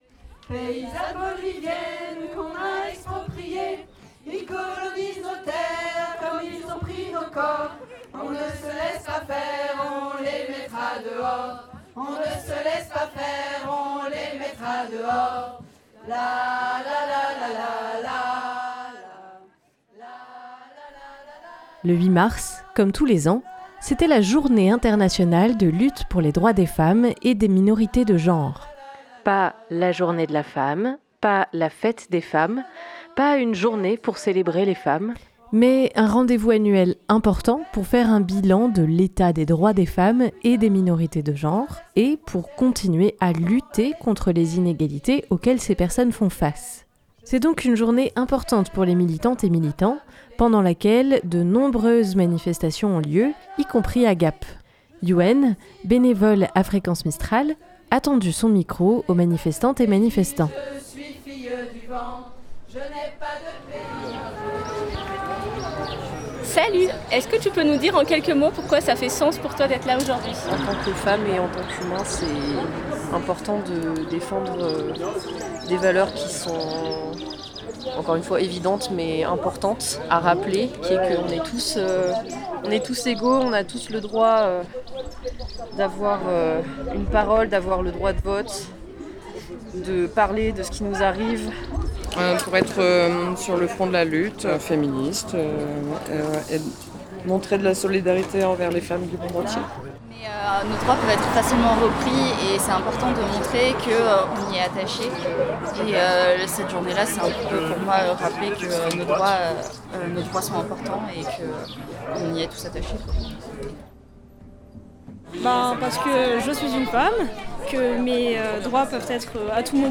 260308 - Manif 08 mars.mp3 (42.5 Mo)